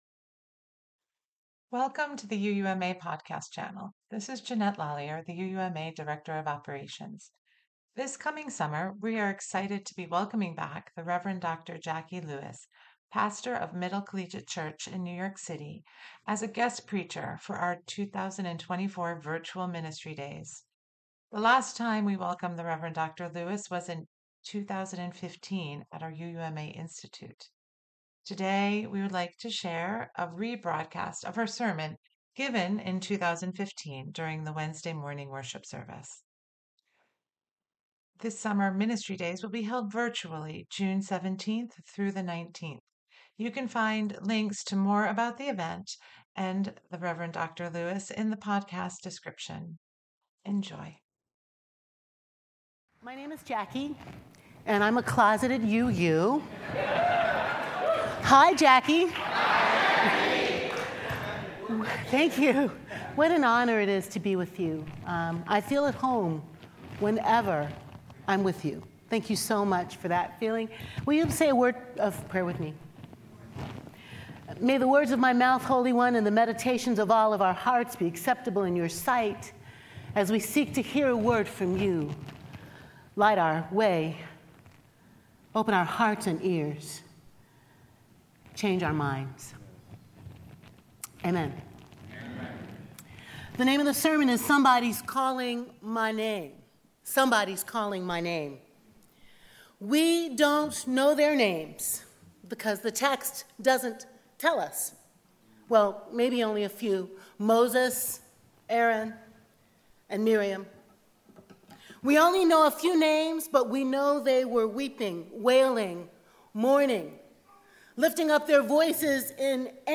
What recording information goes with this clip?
This week our podcast is a a rebroadcast of her sermon given in 2015 during the Wednesday morning worship service.